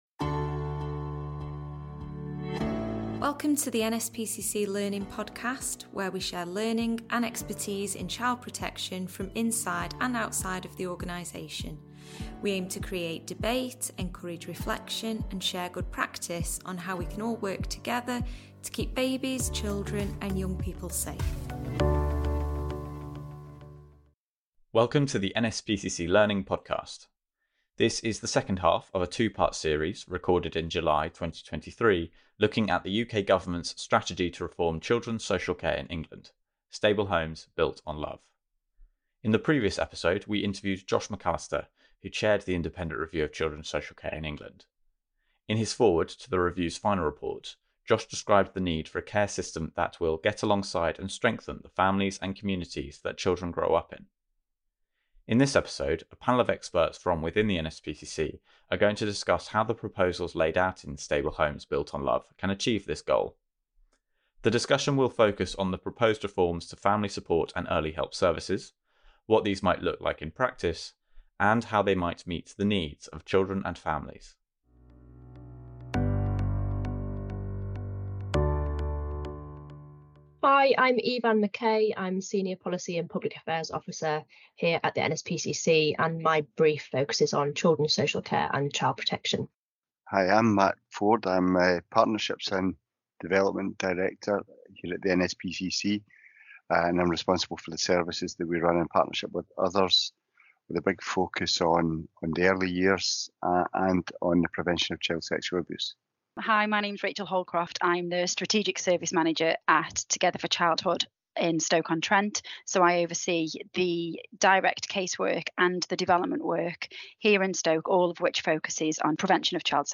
Three experts from the NSPCC discuss proposed reforms to the social care system in England.